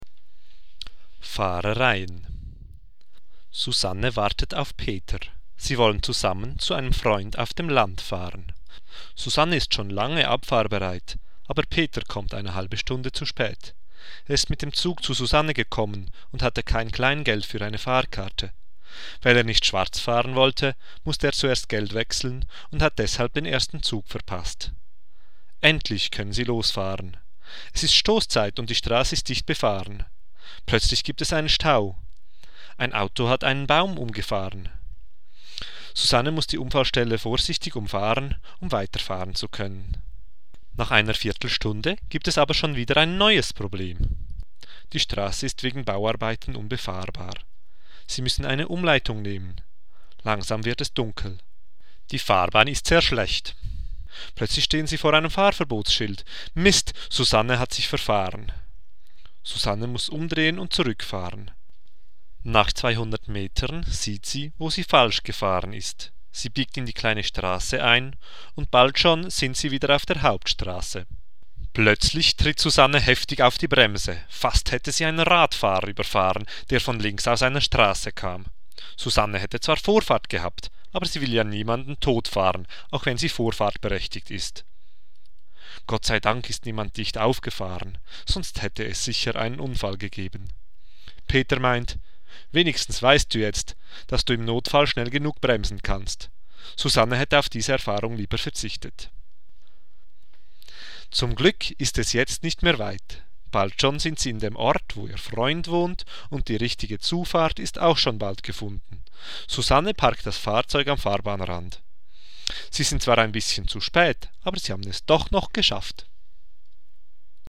Wortaktzent